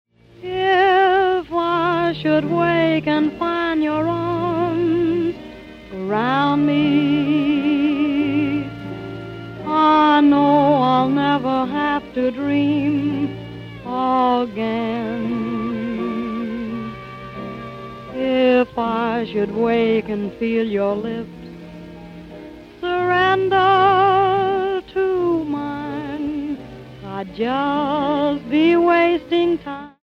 Original recordings from 1931 - 1941, they're all winners.